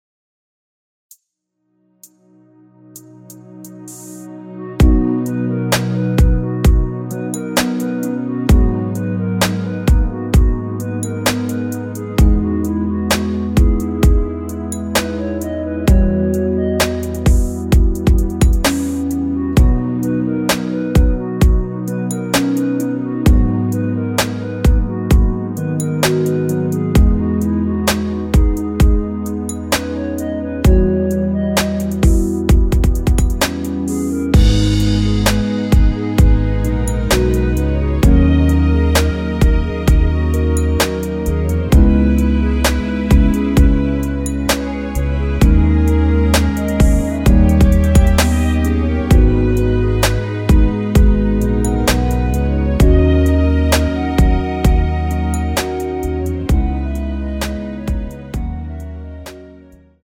원키 멜로디 포함된 MR 입니다.
앞부분30초, 뒷부분30초씩 편집해서 올려 드리고 있습니다.
중간에 음이 끈어지고 다시 나오는 이유는